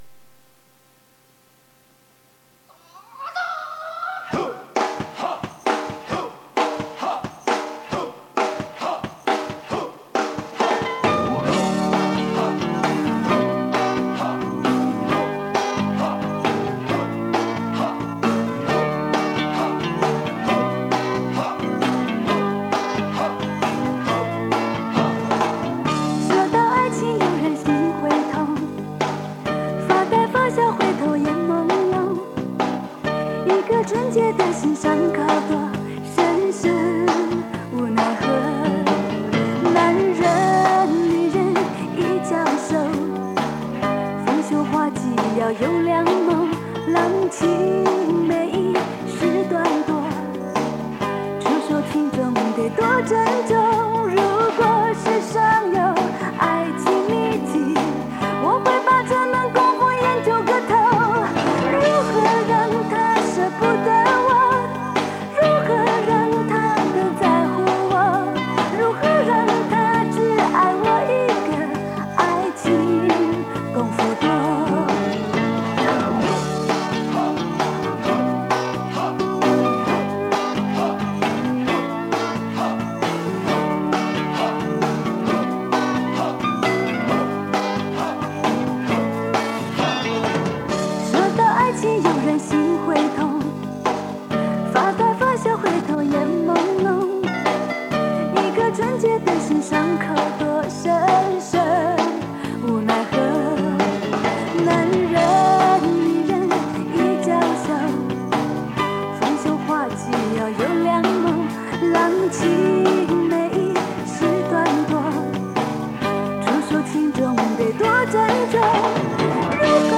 磁带数字化：2022-08-05